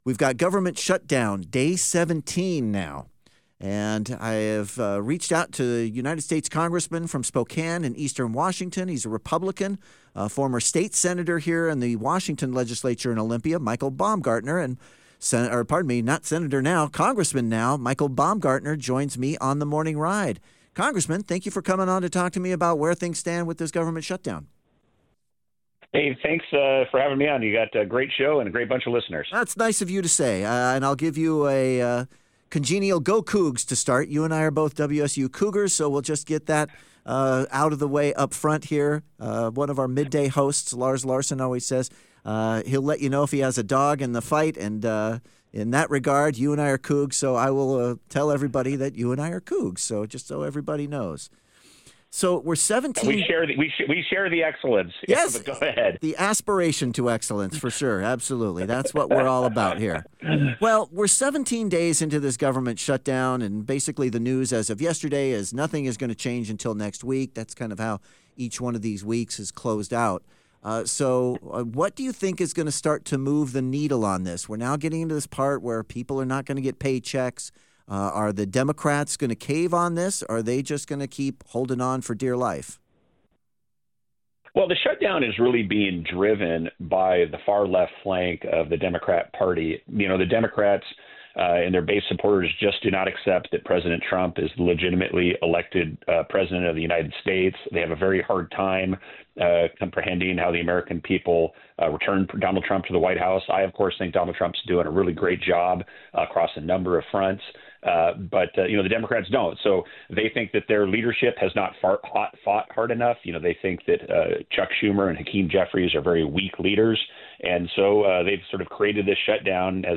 Listen to the full interview with Rep. Michael Baumgartner on The Morning Ride below.